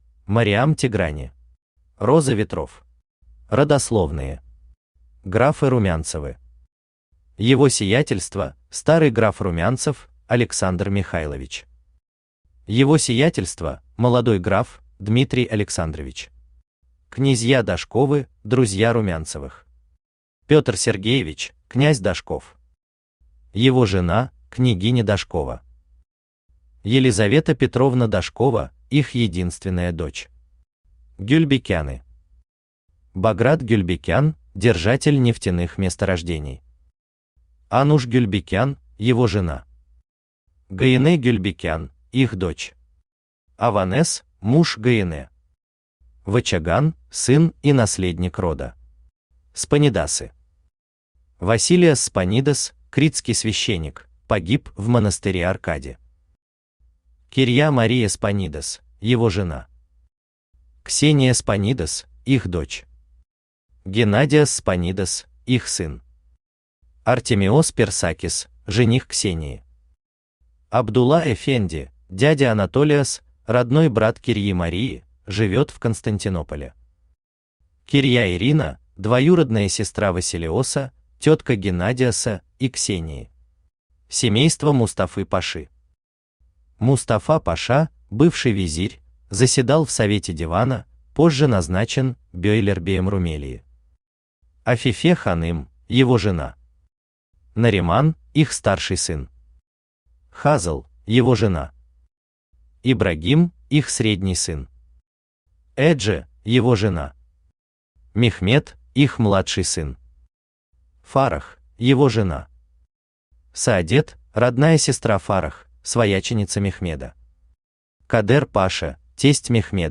Аудиокнига Роза Ветров | Библиотека аудиокниг
Aудиокнига Роза Ветров Автор Мариам Тиграни Читает аудиокнигу Авточтец ЛитРес.